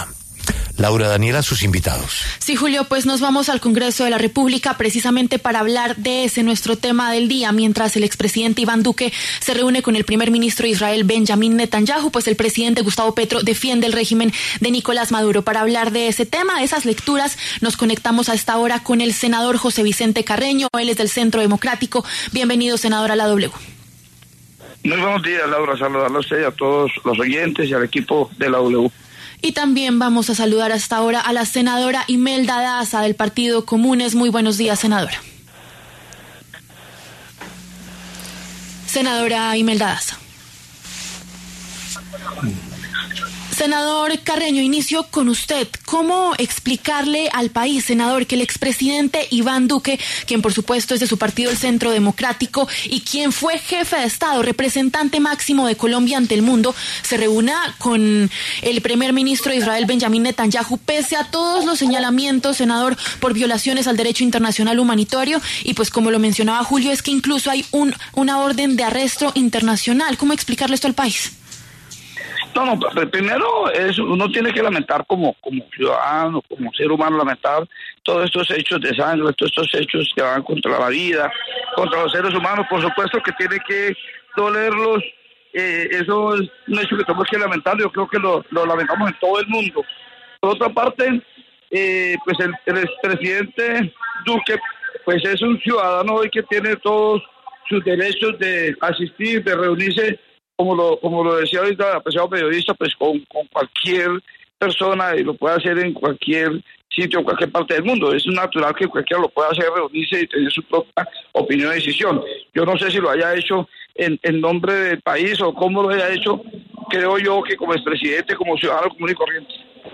Los congresistas José Vicente Carreño, del Centro Democrático, e Imelda Daza, del Partido Comunes, debatieron en La W sobre los relacionamientos de Gustavo Petro e Iván Duque.